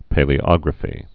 (pālē-ŏgrə-fē)